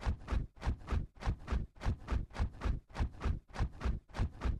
Откройте для себя удивительные звуки бабочек — легкое порхание, едва уловимый шелест крыльев и другие природные оттенки.
Шепот крыльев бабочки